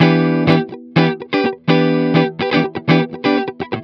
02 GuitarFunky Loop E.wav